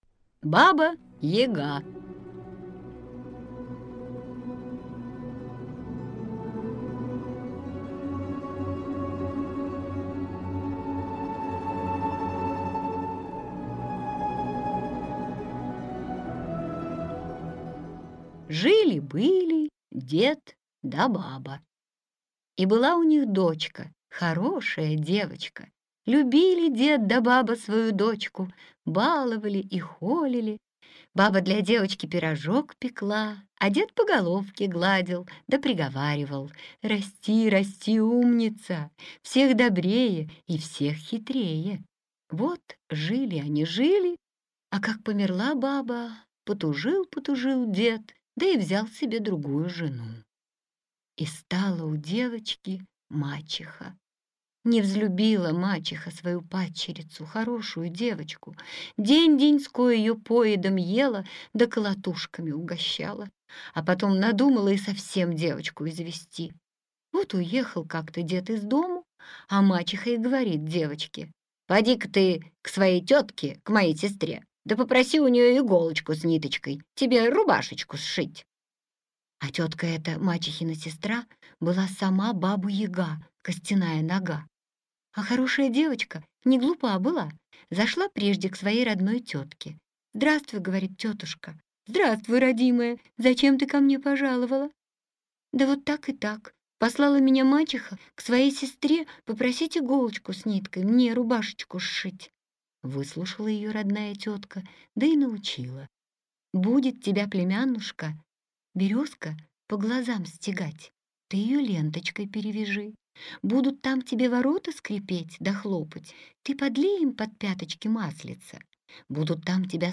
Аудиокнига Сказка за сказкой | Библиотека аудиокниг
Сборник музыкальных сказок